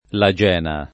[ la J$ na ]